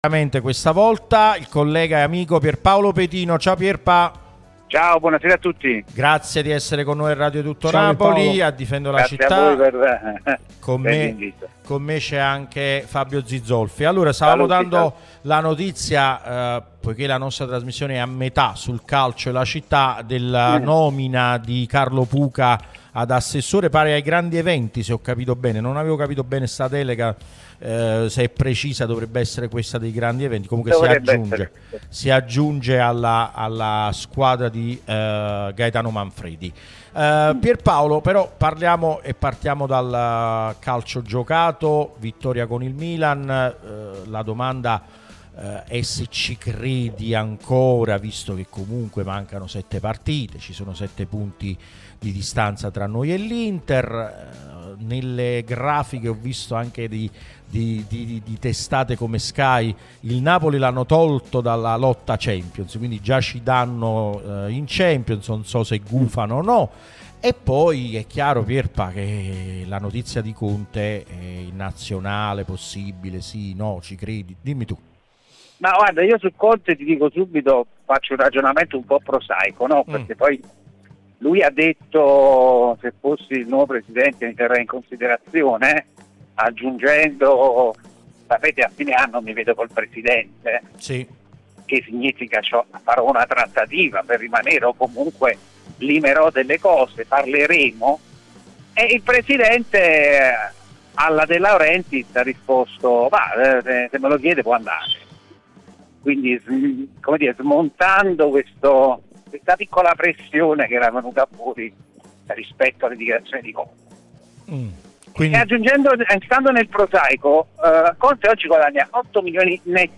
Intervista Radio Tutto Napoli